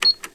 Tecla de una máquina de escribir electrónica
máquina de escribir
Sonidos: Oficina